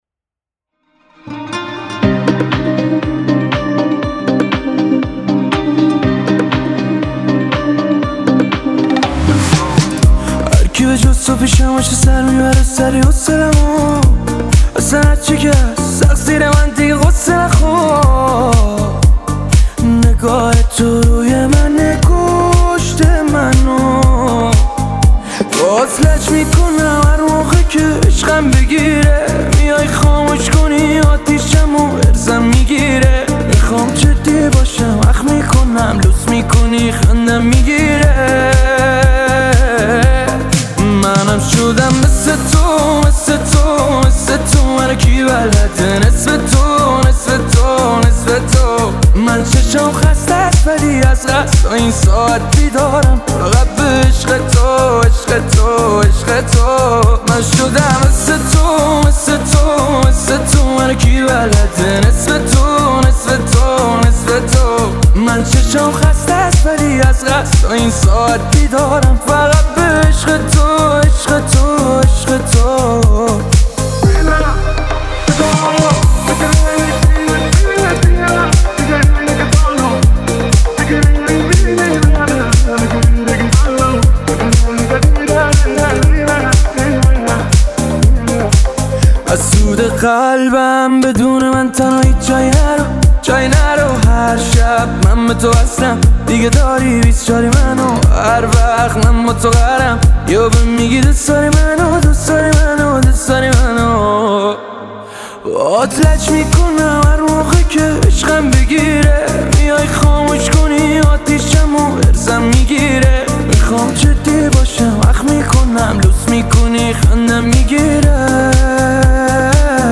با یه ریتم عاشقونه و جذاب